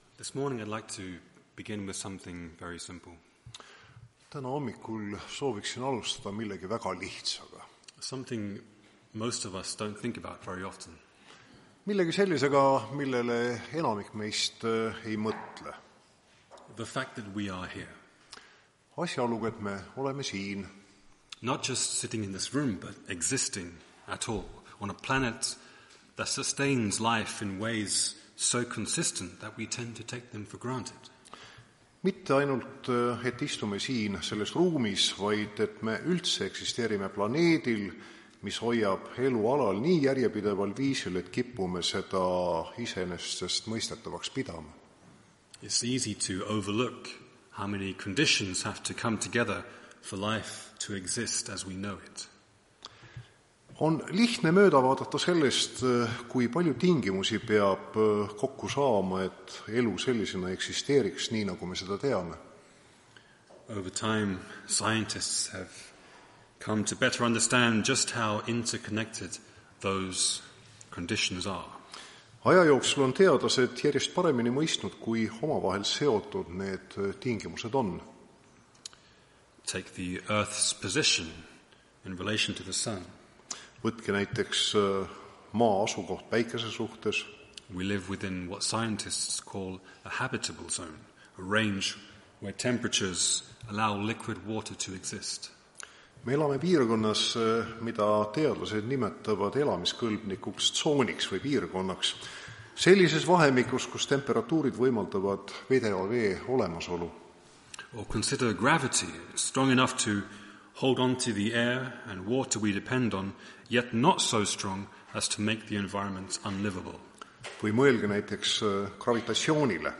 Tartu adventkoguduse 18.04.2026 teenistuse jutluse helisalvestis.